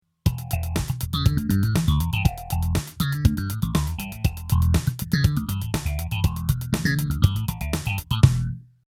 エフェクター・サウンド・サンプル
BOSS PH-2 (phaser)
♪MP3 (ATELIER Z)